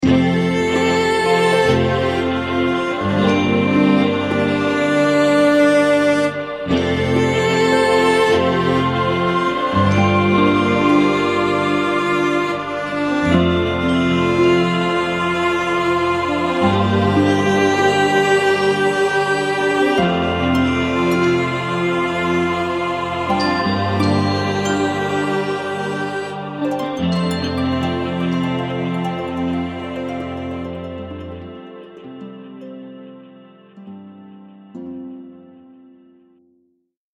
Style: Orchestral